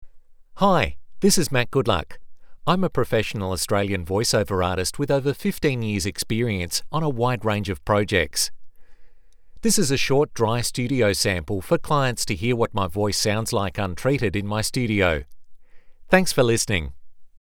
Male
Australian English (Native)
Corporate, Friendly, Reassuring, Smooth, Warm, Approachable, Authoritative, Bright, Character, Conversational, Deep, Engaging, Gravitas, Natural
South Australian (native)
My voice can range from the Australian equivalent of received pronunciation to a subtle articulate and warm delivery to a broad, ‘ocker’ Aussie accent depending on the needs of your project.
Microphone: Audio Technica AT4050
Audio equipment: Custom isolation booth, Focusrite Scarlett 18i8 interface, Grace M101 pre-amp